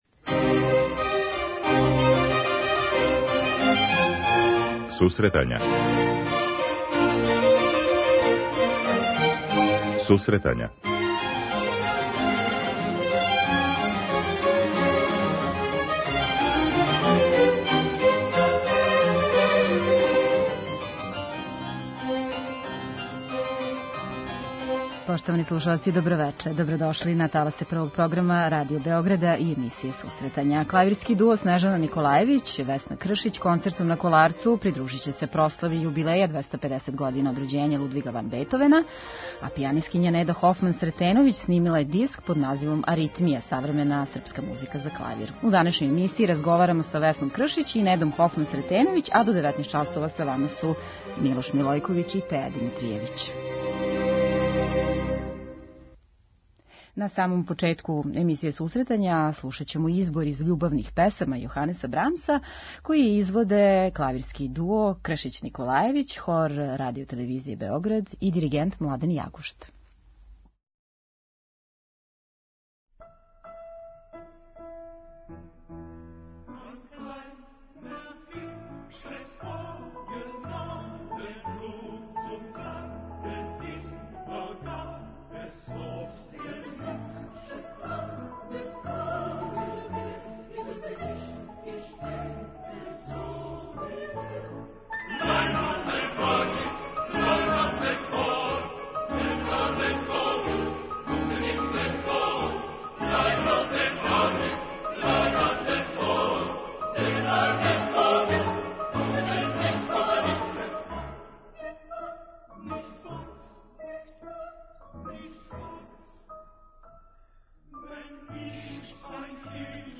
преузми : 9.61 MB Сусретања Autor: Музичка редакција Емисија за оне који воле уметничку музику.